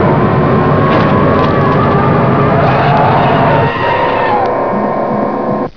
The credits roll with Ash's scream of defeat (